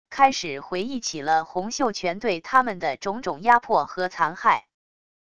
开始回忆起了洪秀全对他们的种种压迫和残害wav音频生成系统WAV Audio Player